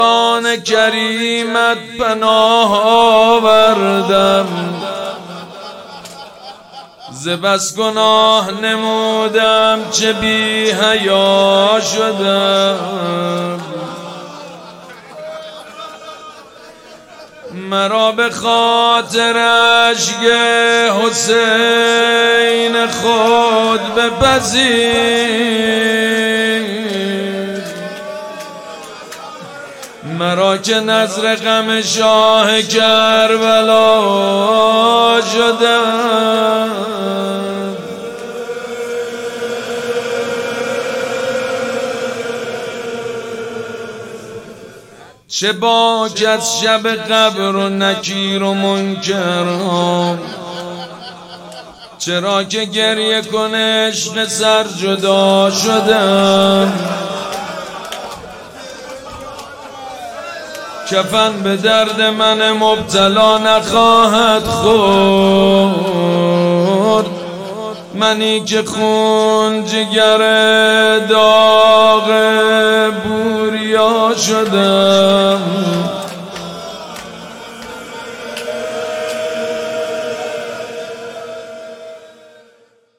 حسینیه ریحانة‌الحسین (سلام‌الله‌علیها)
مناجات
مداح
حاج سید مجید بنی فاطمه